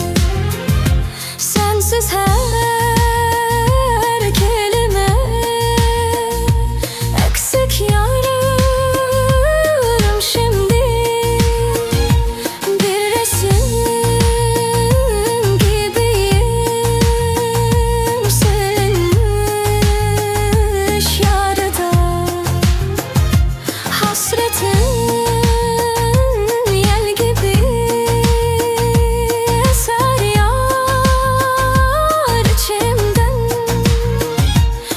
Жанр: Фолк